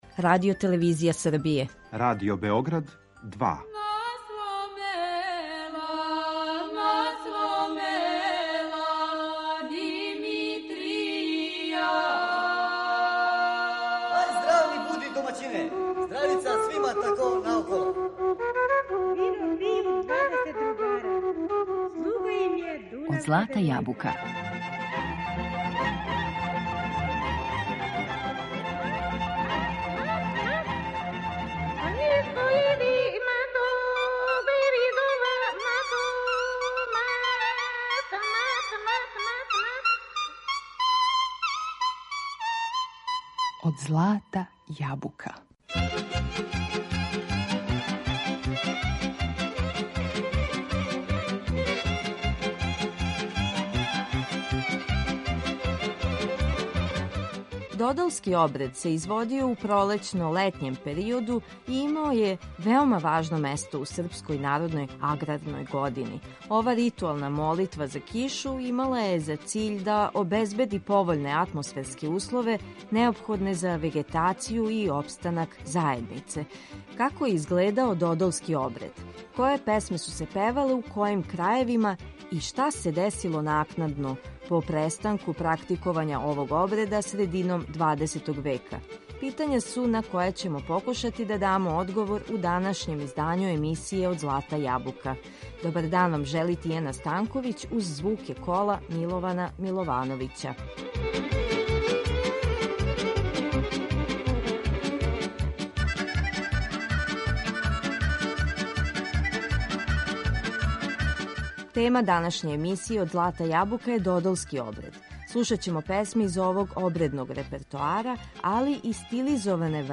На репертоару су документарни снимци додолских песама, као и њихове стилизоване варијанте.